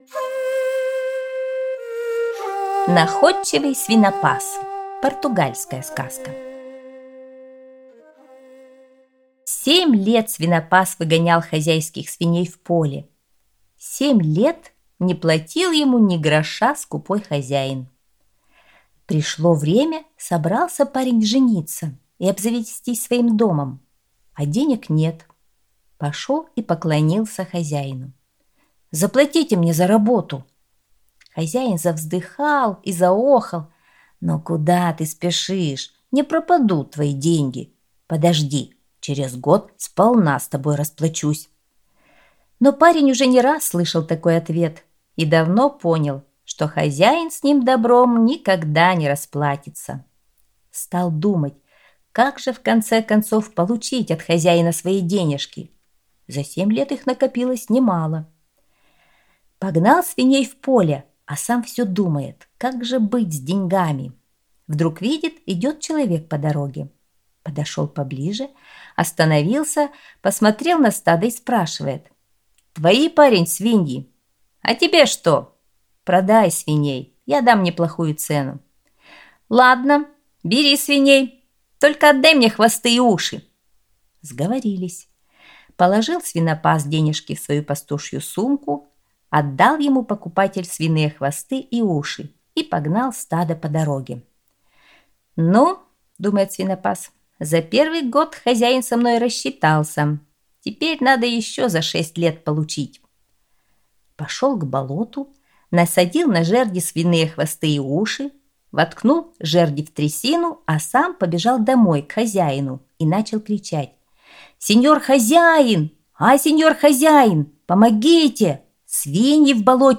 Аудиосказка «Находчивый свинопас»